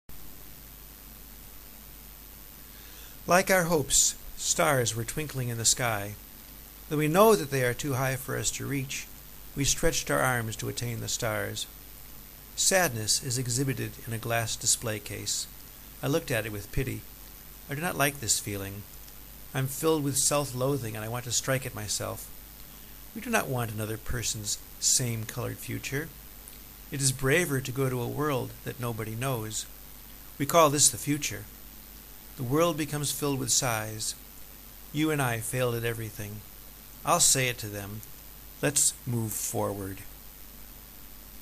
I hope I didn't speak too fast.